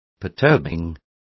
Complete with pronunciation of the translation of perturbing.